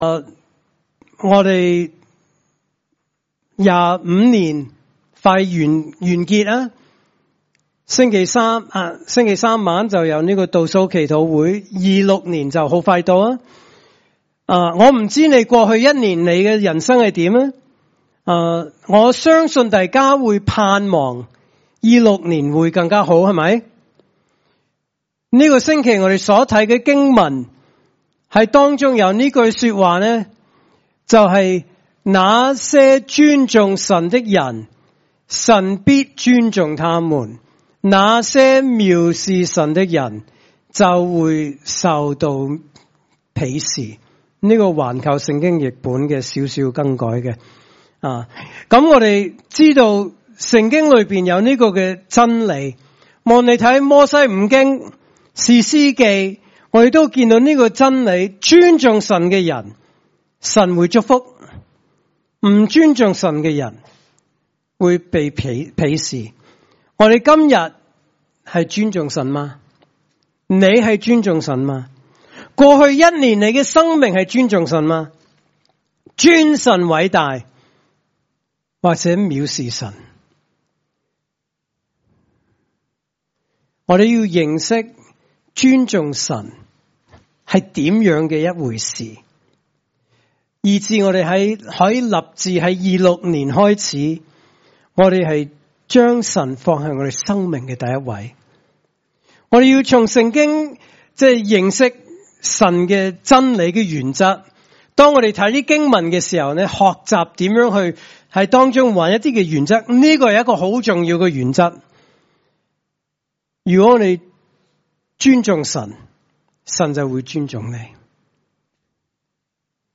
來自講道系列 "解經式講道"